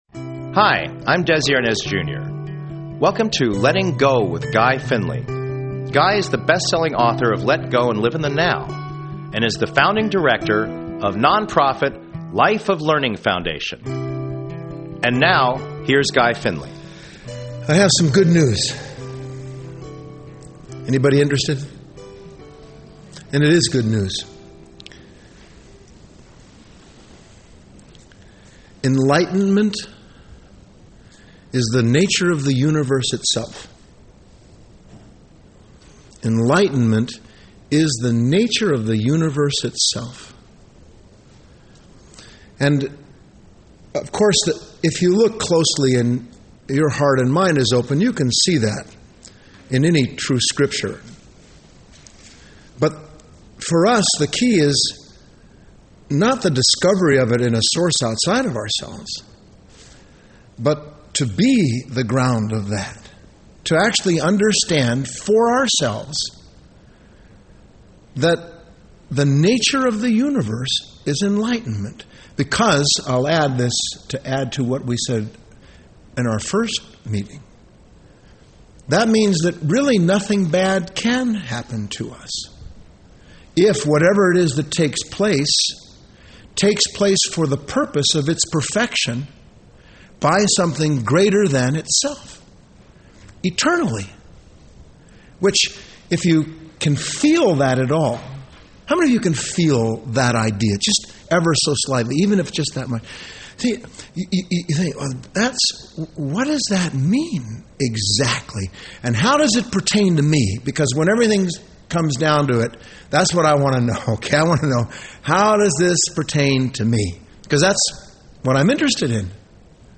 Talk Show Episode, Audio Podcast, Letting_Go_with_Guy_Finley and Courtesy of BBS Radio on , show guests , about , categorized as